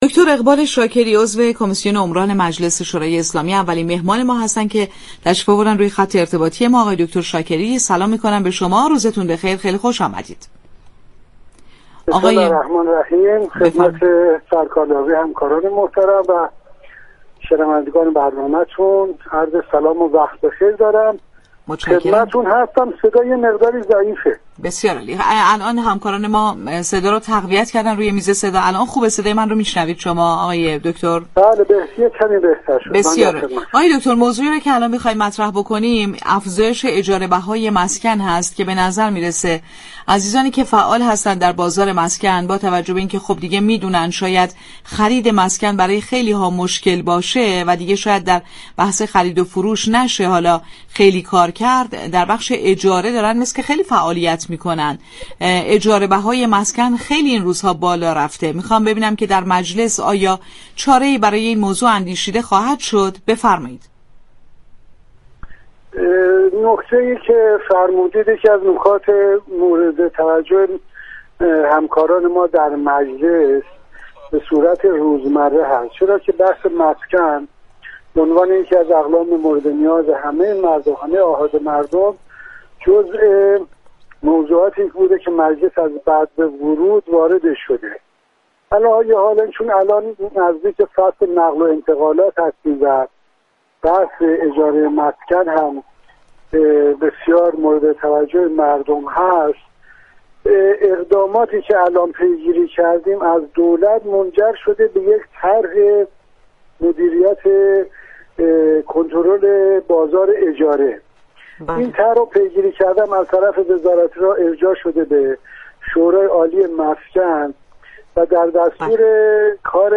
میهمانان تلفنی این برنامه بودند.